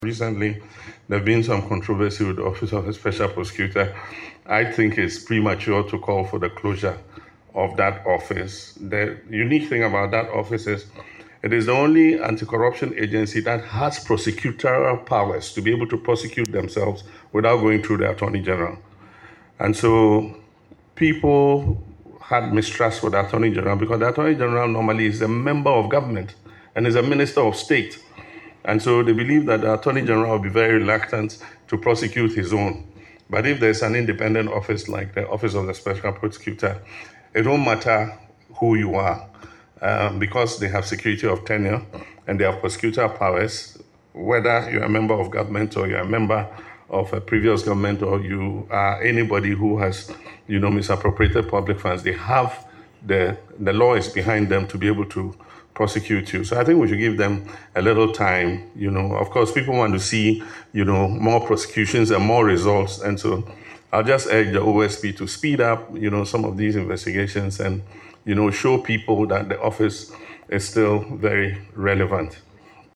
Speaking at the Presidency during a meeting with the National Peace Council, President Mahama underscored the OSP’s unique mandate and independence, which he said remain essential for building public confidence in the country’s fight against corruption.
LISTEN TO PRESIDENT MAHAMA IN THE AUDIO BELOW: